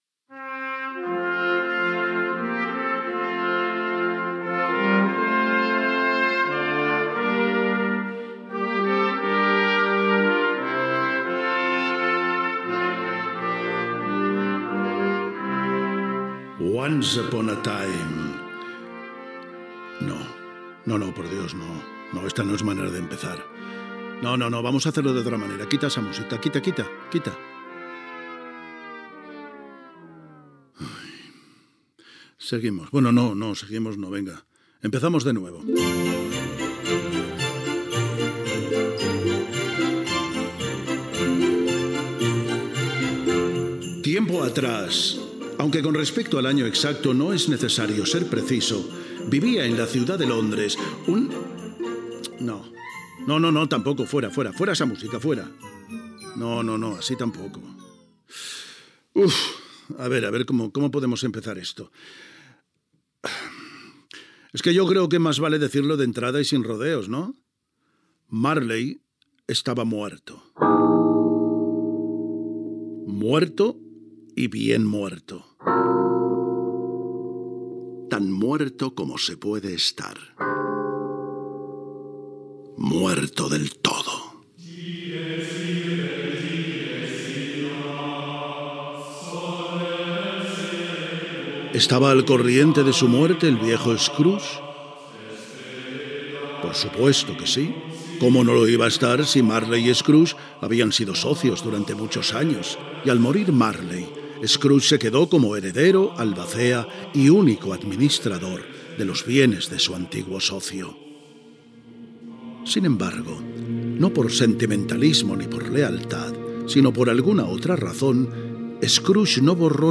9c34375a349b4abd821c3a094259f722c8e49f04.mp3 Títol Cadena SER Emissora Ràdio Barcelona Cadena SER Titularitat Privada estatal Nom programa Canción de Navidad Descripció Adaptació radiofònica de "Canción de Navidad" de Charles Dickens. Diversos intents de començar el programa.
Gènere radiofònic Ficció